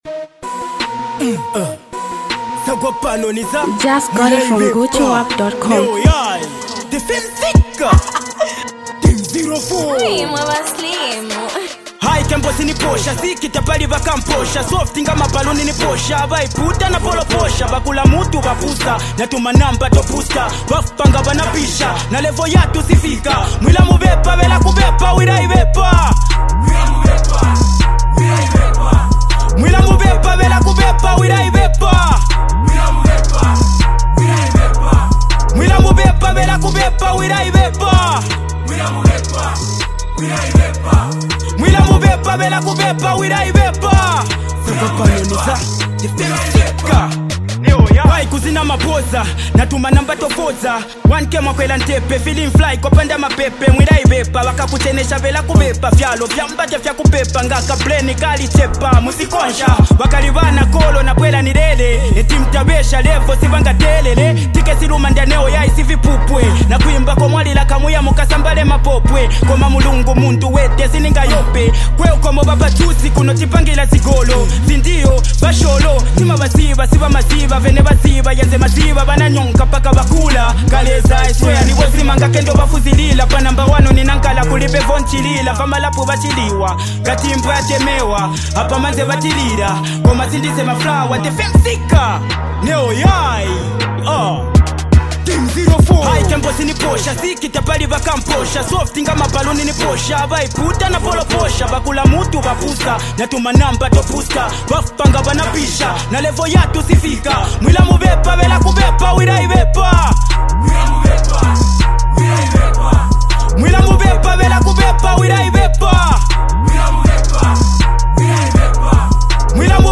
Zambian Mp3 Music
buzzing street anthem record